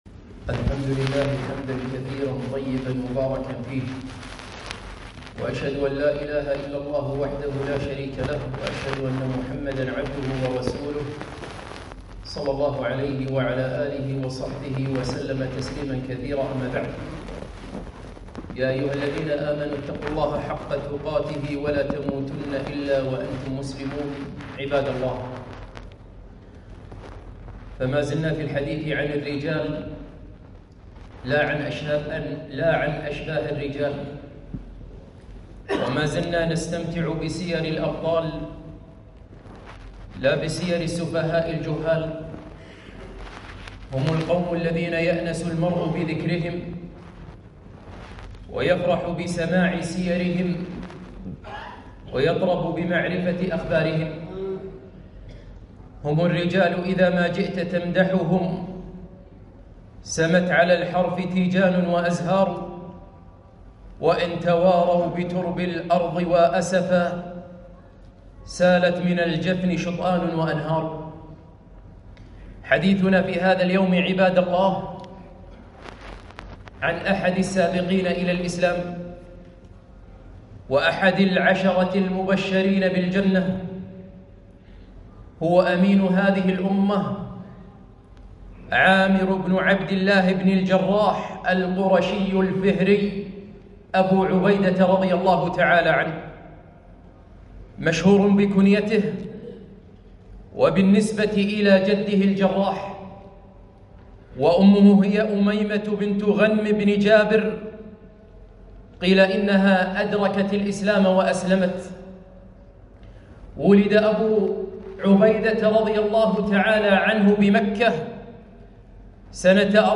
خطبة - أبو عبيدة بن الجراح رضي الله عنه